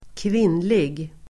Uttal: [²kv'in:lig]